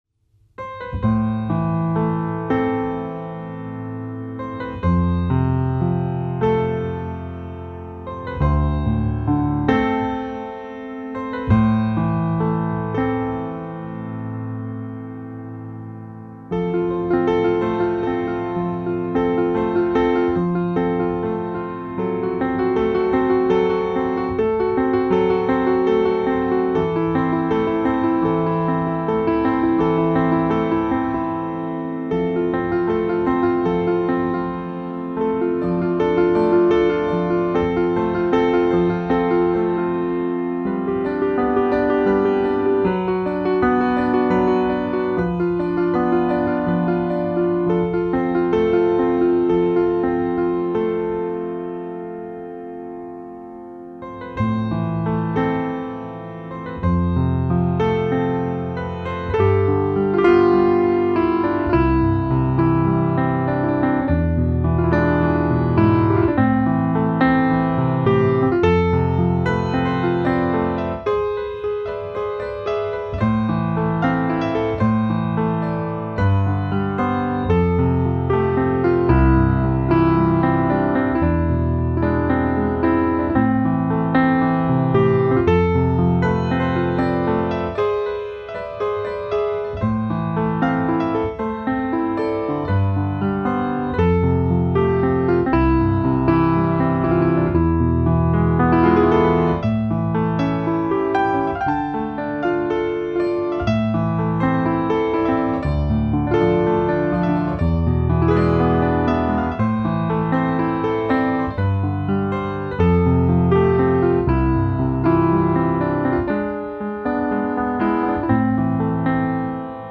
آهنگساز و پیانو